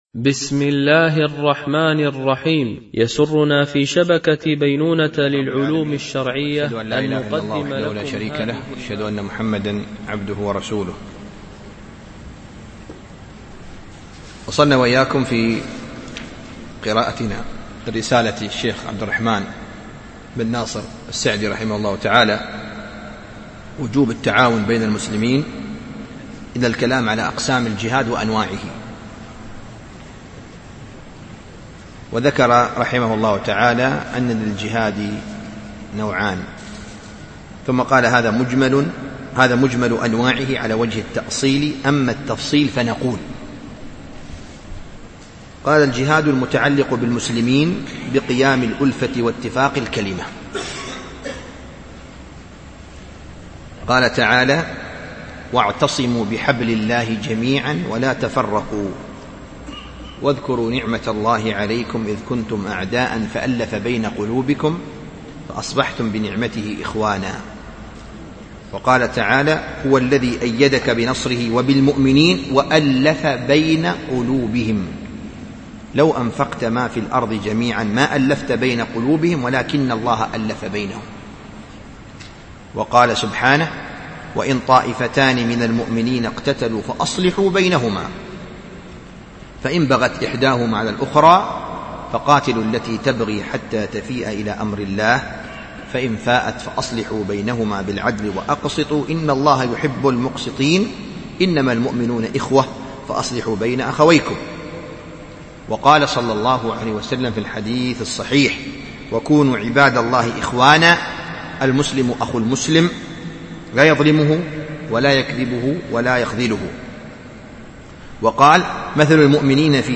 رسالة في وجوب التعاون بين المسلمين لابن السعدي ـ الدرس الثالث
MP3 Mono 22kHz 32Kbps (CBR)